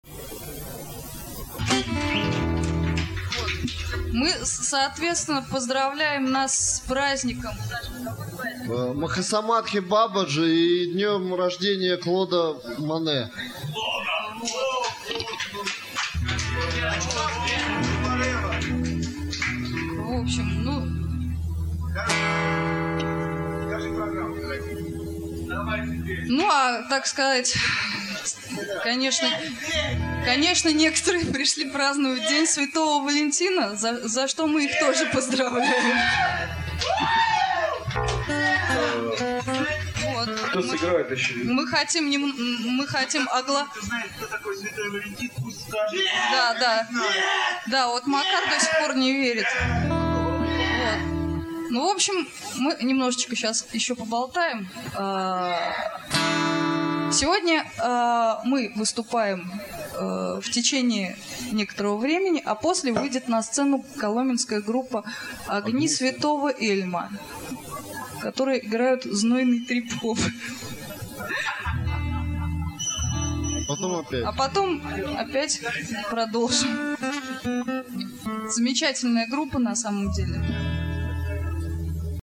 Концерт 14 февраля